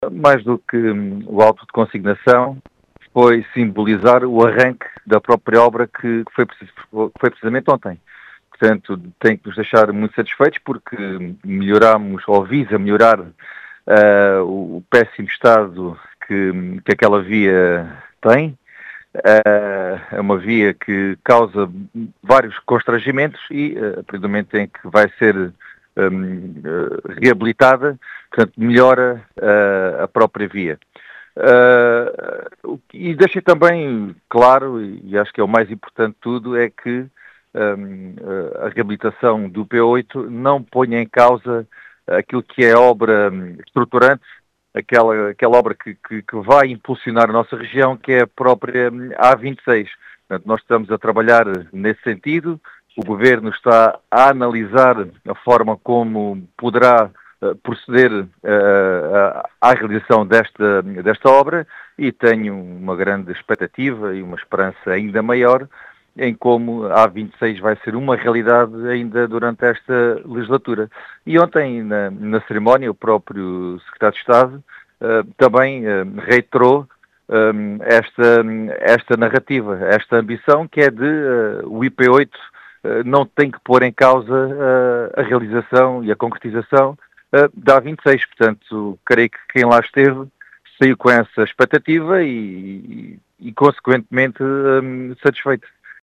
Em declarações à Rádio Vidigueira, Gonçalo Valente, deputado da AD eleito pelo distrito de Beja, fala num momento simbólico, para a região, destacando que a reabilitação do IP8 “não põe em causa” a “obra estruturante”, que é a A26, dizendo que esta será uma realidade “ainda” nesta legislatura.